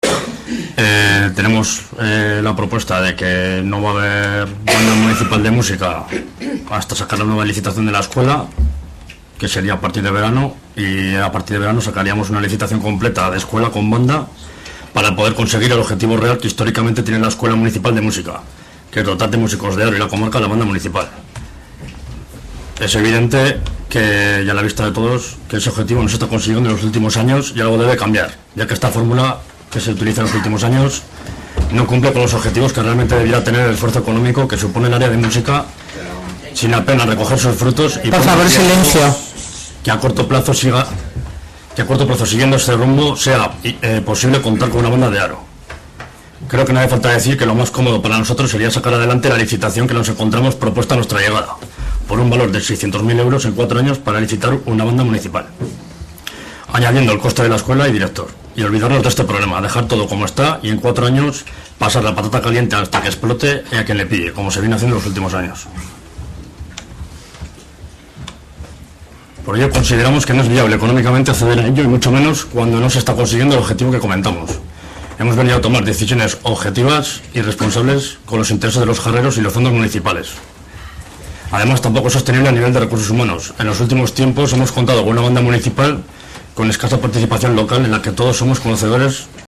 El concejal de Banda, Borja Merino, contaba así el proyecto de agrupación en el que trabaja el equipo de gobierno.